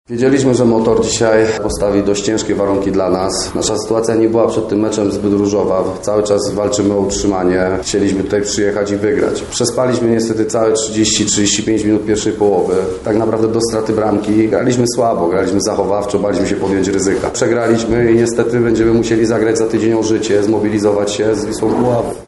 Oto co powiedzieli podczas pomeczowej konferencji prasowej przedstawiciele obu ekip: